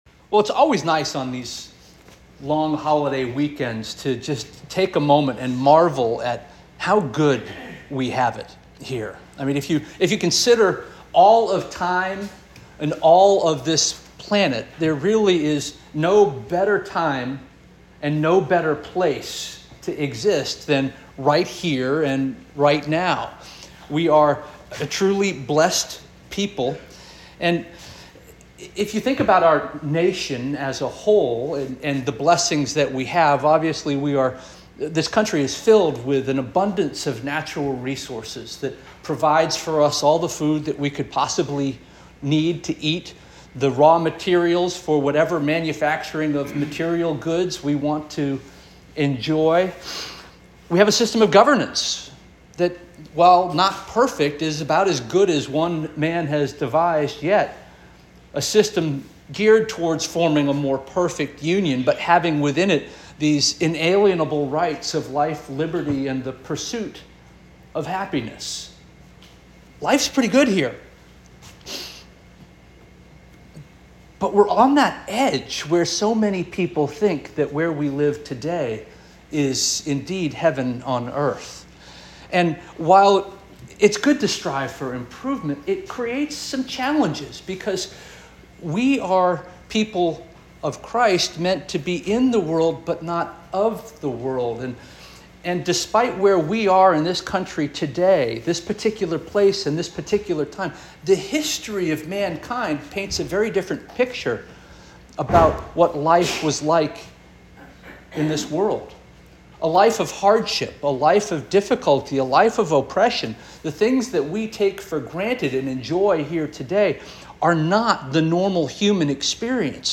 Sept 1 2024 Sermon - First Union African Baptist Church